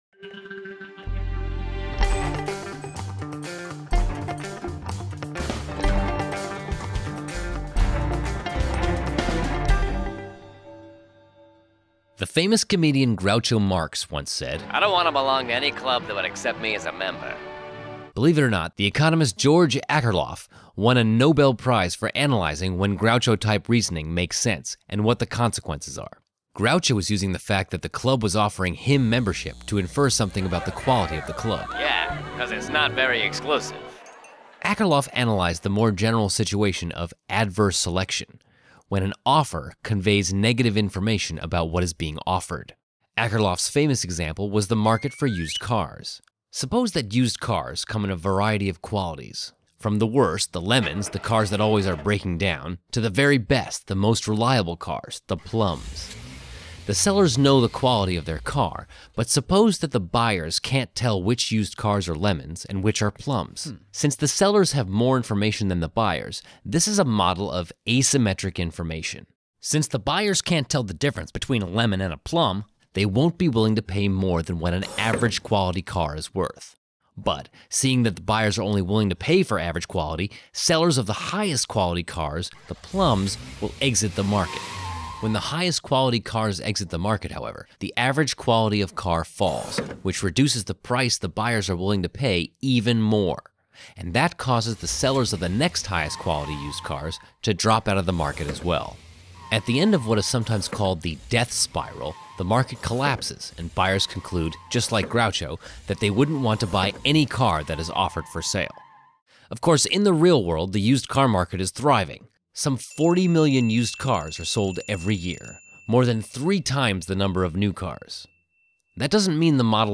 Instructor: Alex Tabarrok, George Mason University